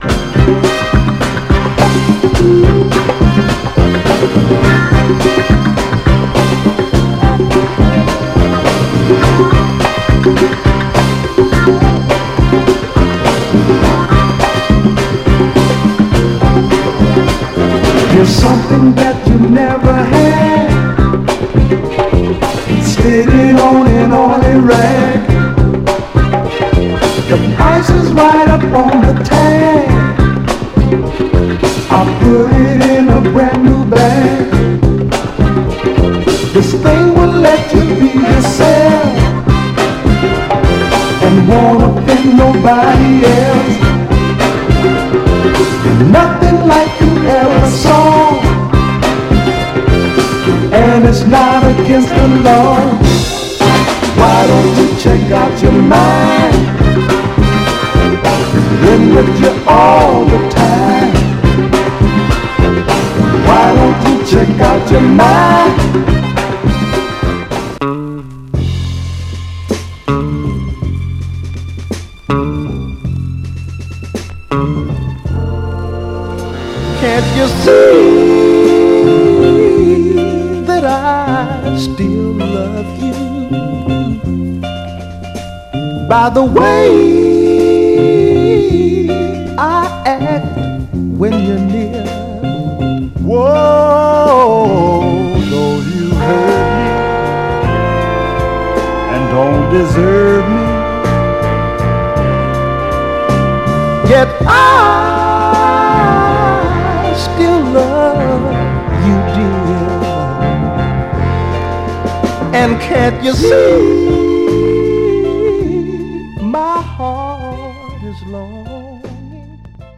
サントラ感のあるスリリングなシカゴ・ファンク〜ドープなミッド・ソウルです！
※試聴音源は実際にお送りする商品から録音したものです※